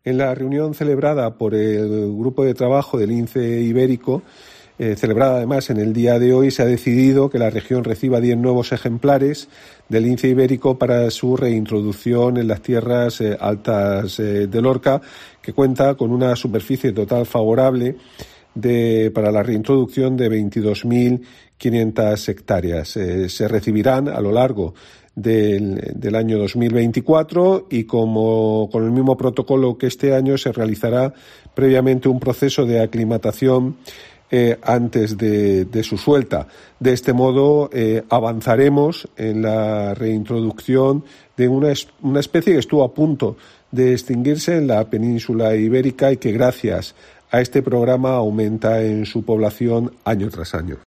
Juan María Vázquez, consejero de Medio Ambiente, Universidades, Investigación y Mar Menor